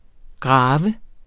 Udtale [ ˈgʁɑːvə ]